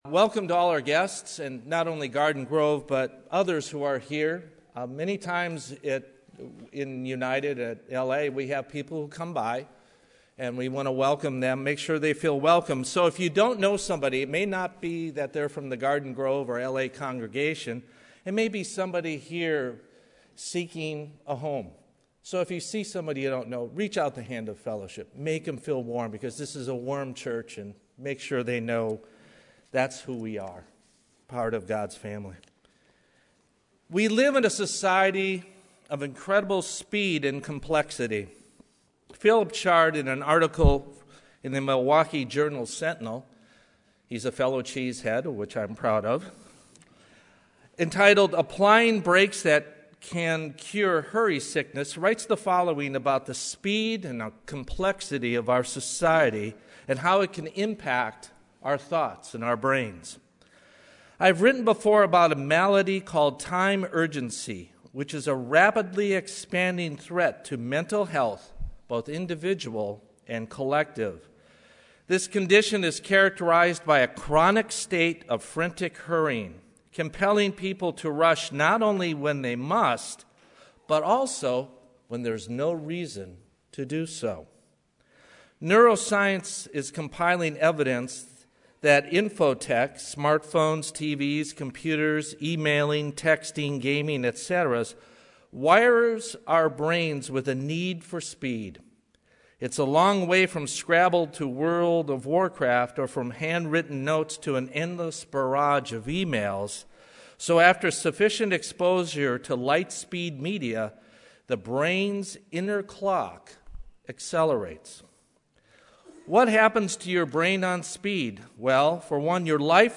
We live in a culture that subjects us to a frantic pace, dizzying distractions, increasing complexities, constant change and growing demands on our time. Given during the Days of Unleavened Bread, this message explains, from a biblical perspective, the need to simplify our lives and establish proper priorities for our mental, physical and spiritual health.